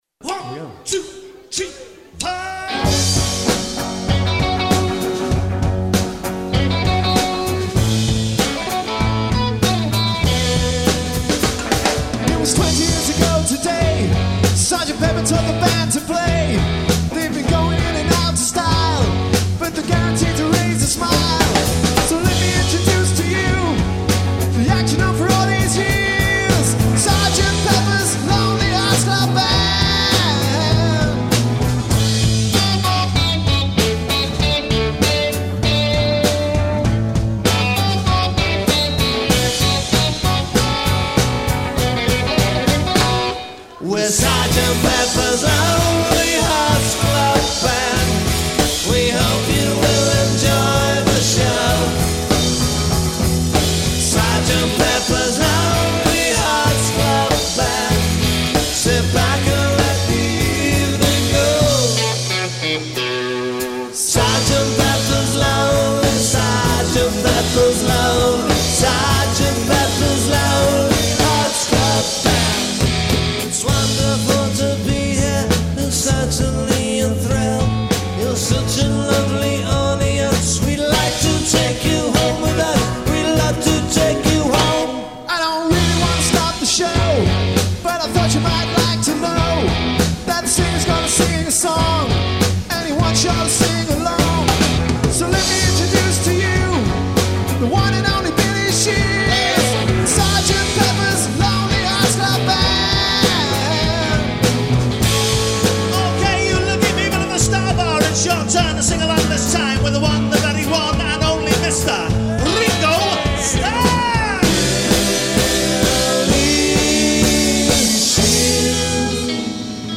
Live recording-